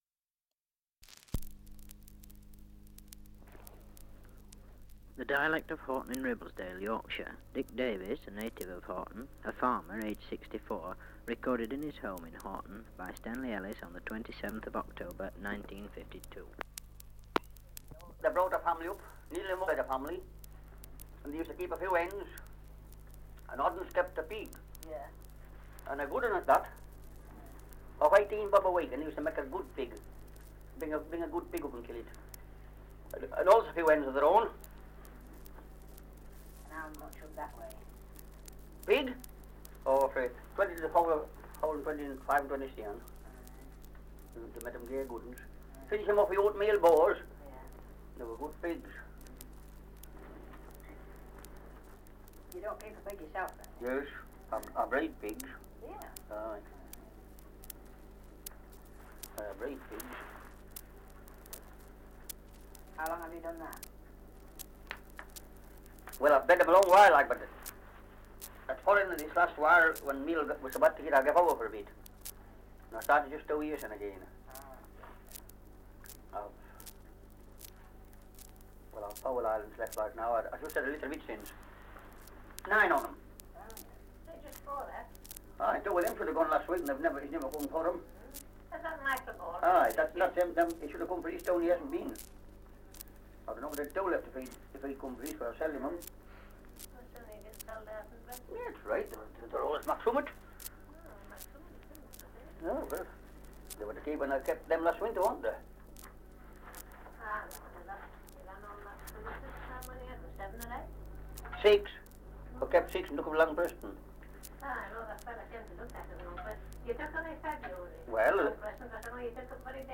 2 - Survey of English Dialects recording in Pateley Bridge, Yorkshire. Survey of English Dialects recording in Horton-in-Ribblesdale, Yorkshire
78 r.p.m., cellulose nitrate on aluminium